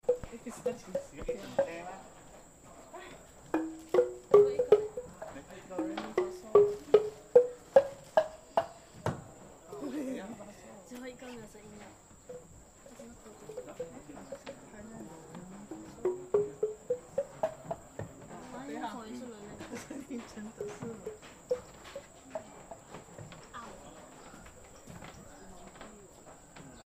Longhouse xylophone